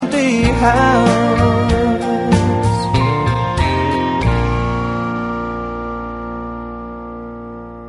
E9th Tuning HARMONIC ENDING